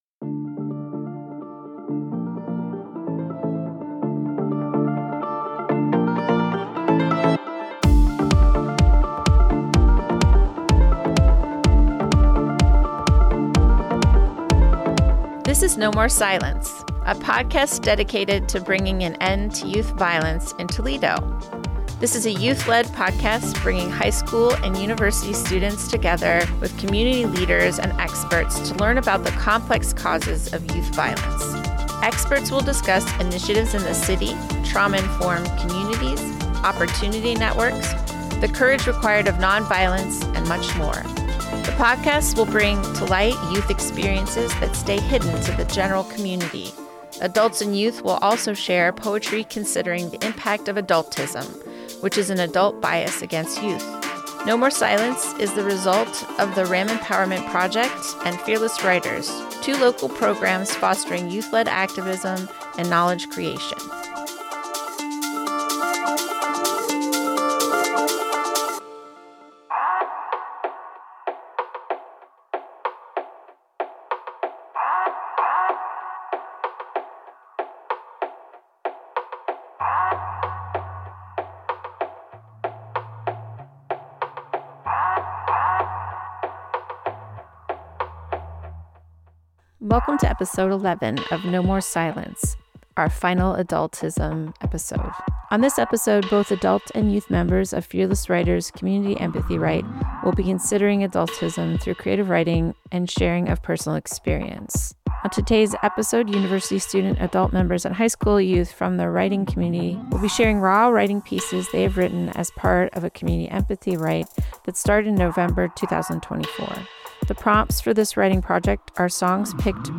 On this episode, both adult and youth members of Fearless Writers Community Empathy Write will be considering adultism through creative writing and sharing of personal experience.